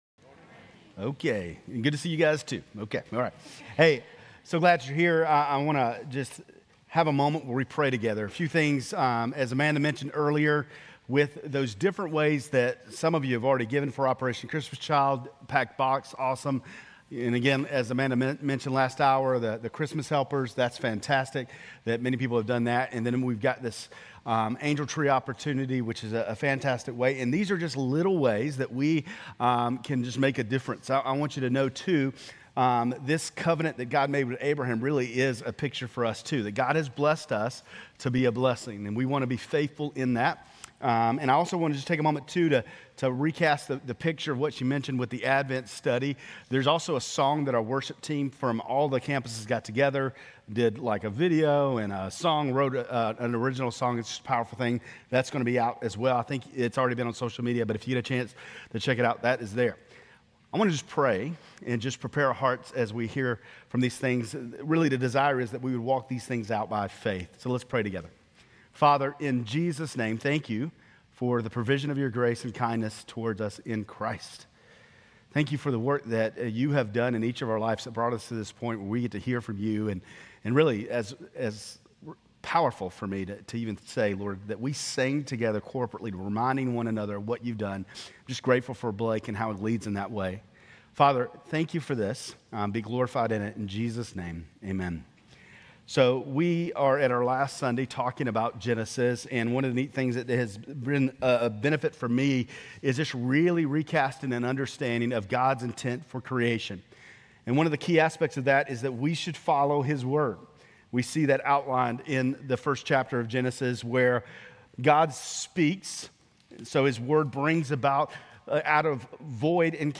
Grace Community Church Lindale Campus Sermons Gen 37-50 - Joseph Nov 24 2024 | 00:30:41 1x 00:00 / 00:30:41 Subscribe Share RSS Feed Share Link Embed